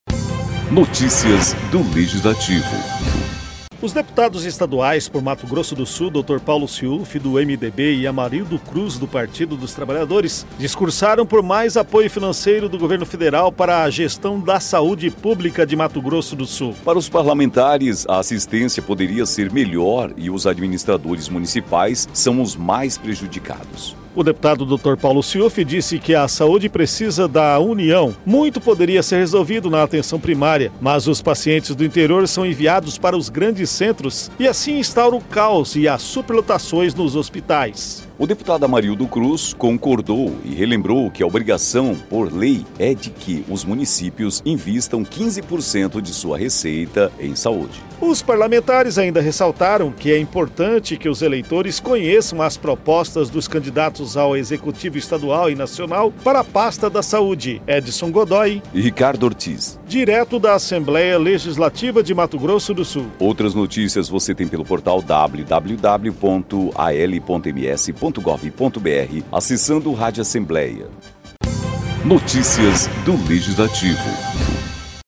Os deputados estaduais por Mato Grosso do Sul, Dr. Paulo Siufi (MDB) e Amarildo Cruz (PT), discursaram por mais apoio financeiro do Governo Federal para a gestão da Saúde Pública.
“A Saúde precisa da União. Muito poderia ser resolvido na atenção primária, mas os pacientes do interior são enviados para os grandes centros e instaura o caos e as superlotações. Filas e falta de cirurgias, morte enquanto espera. O Sistema Único de Saúde [SUS] está falido. As prefeituras recorrem ao Estado e tem muita tragédia anunciada. Esses dias ajudei a socorrer vítimas de um acidente entre duas motocicletas e a ambulância demorou 43 minutos para chegar. Lamentável”, disse Siufi, na tribuna.